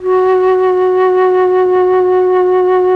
RED.FLUT1 12.wav